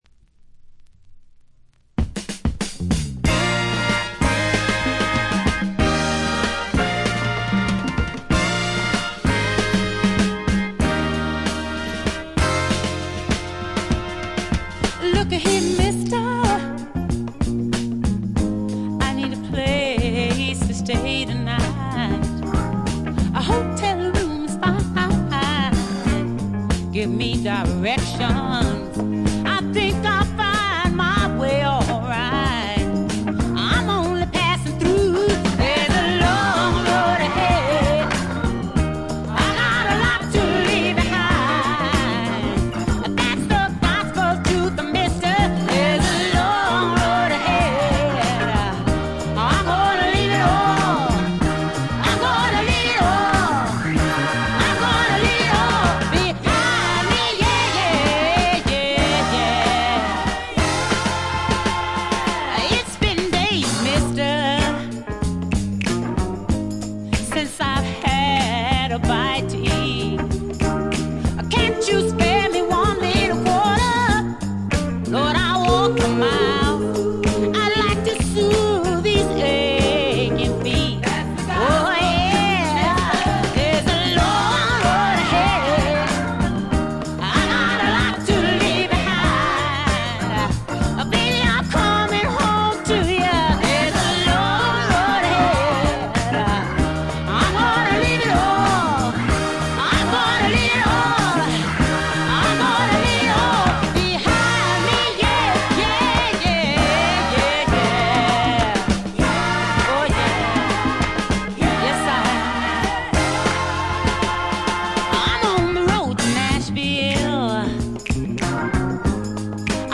スワンプ強力盤。
Piano, Organ
Drums